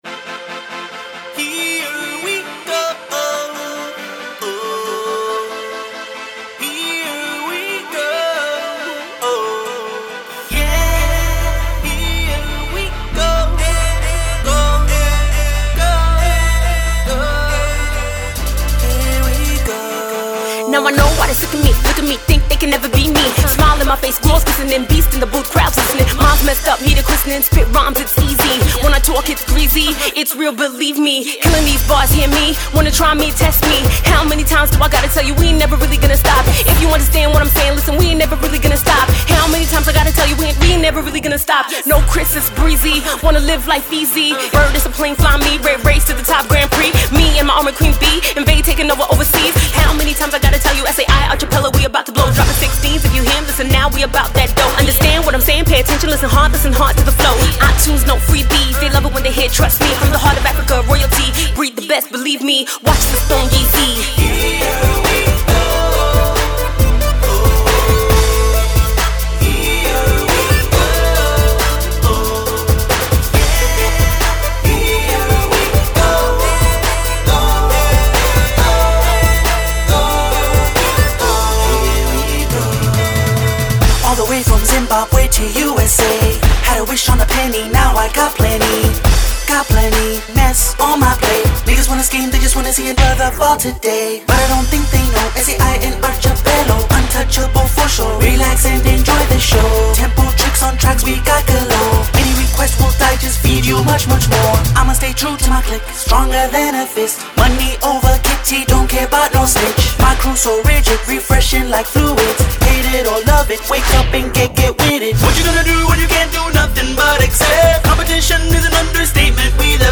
African femcee
club banger